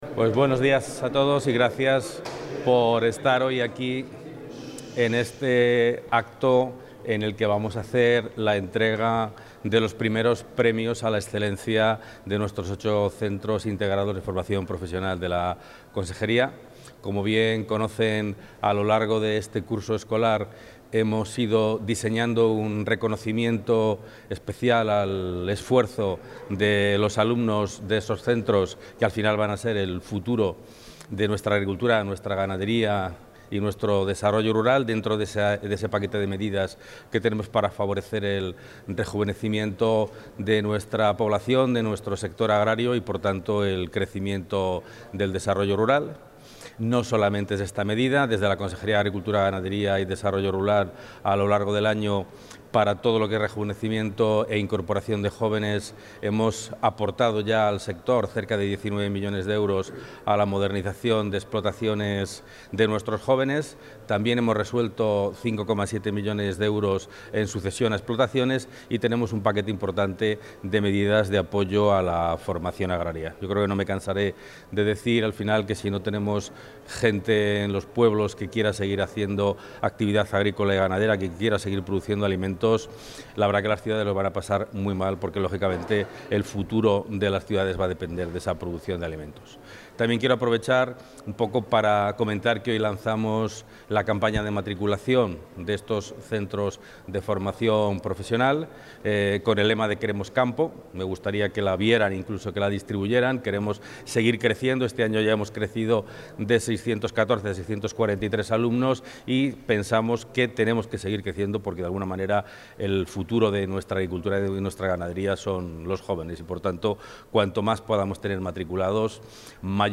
Intervención del consejero (6.134 kbytes).